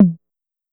Tom (Everything We Need).wav